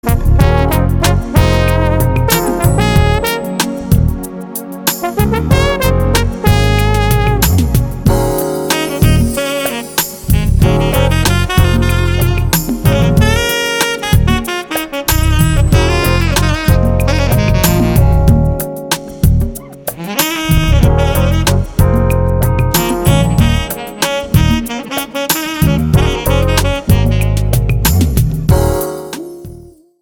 EASY LISTENING  (02.11)